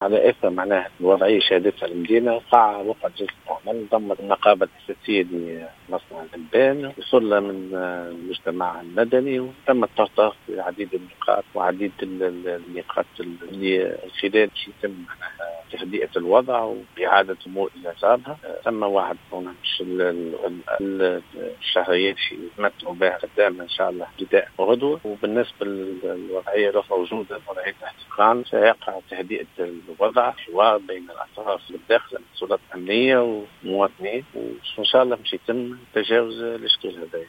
كما تم الاتفاق وفق تصريح التيساوي للجوهرة اف ام، على تهدئة الوضع بين السلط الأمنية والمواطنين وتجاوز الاشكال القائم منذ أيام في المنطقة.